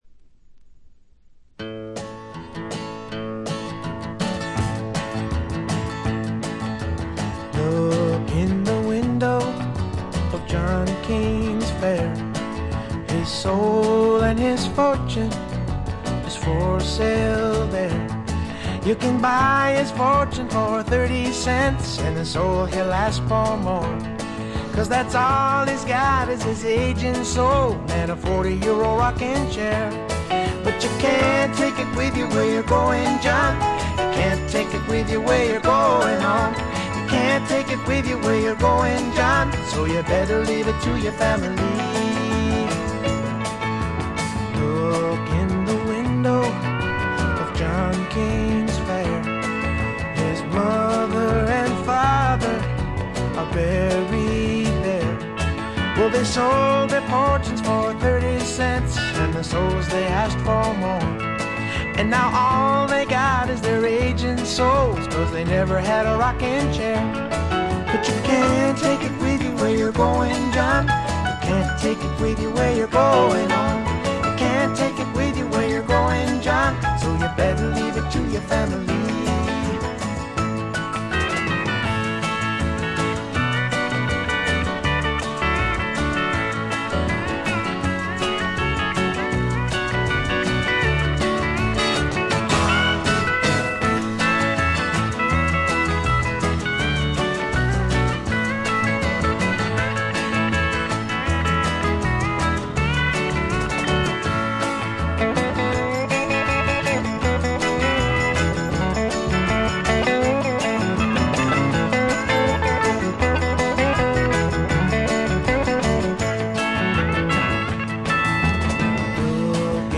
カントリー風味を加えています。カントリーロック的な曲とフォークロック的な曲の組み合わせ加減もとてもよいです。
Guitar, Guitar (Rhythm), Vocals
Drums, Vocals
Organ, Dobro, Harmonica, Vibraphone